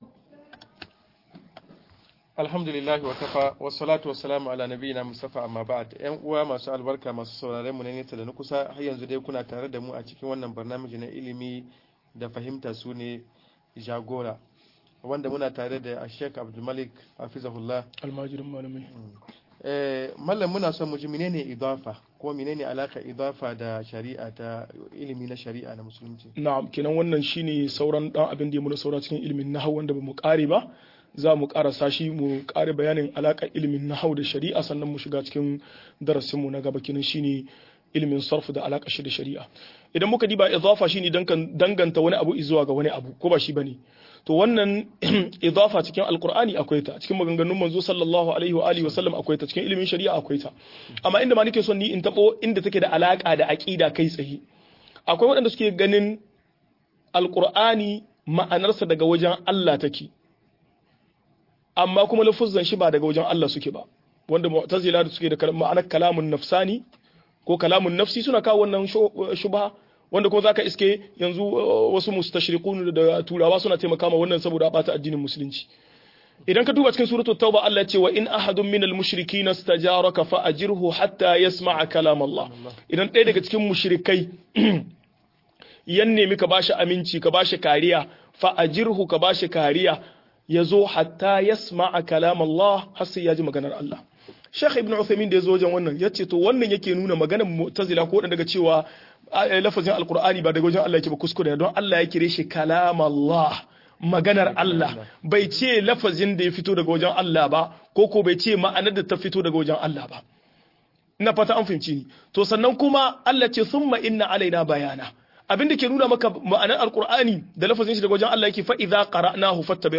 Sarfu da alakar sa da shari'a - MUHADARA